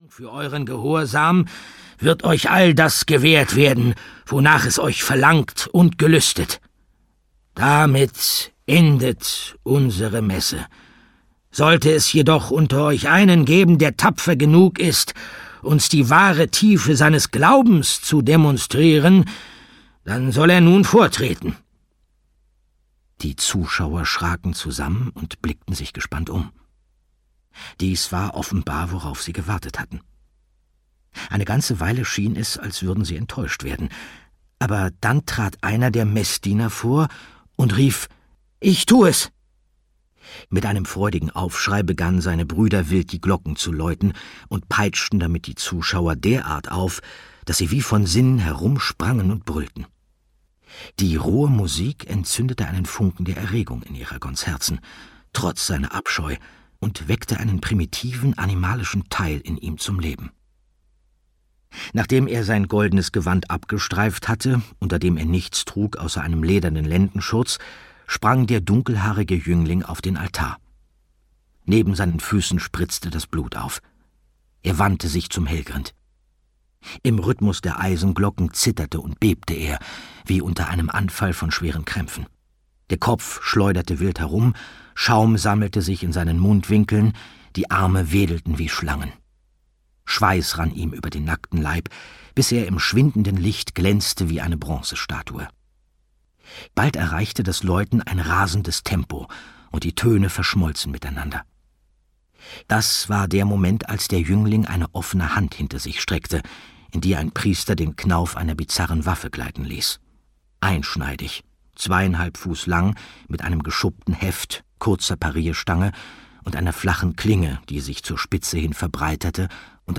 Hörbuch Eragon - Die Weisheit des Feuers von Christopher Paolini.
Ukázka z knihy
• InterpretAndreas Fröhlich